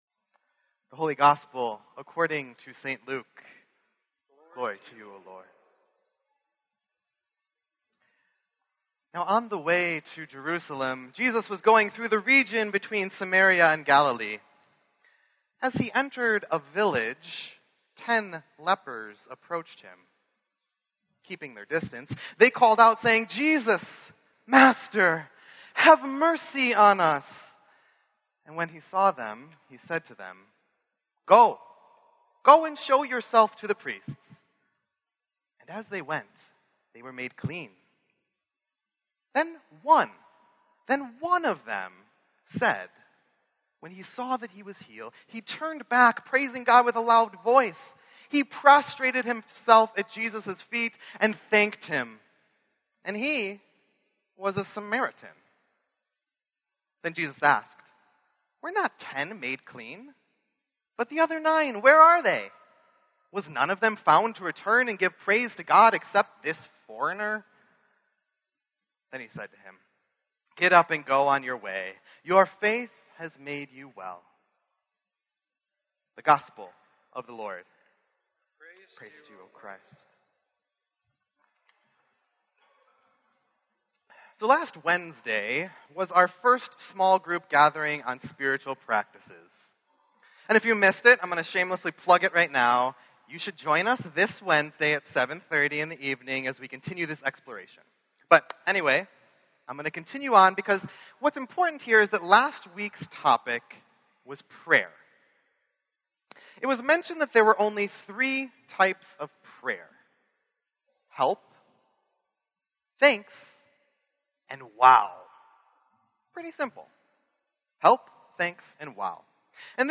Sermon_10_9_16.mp3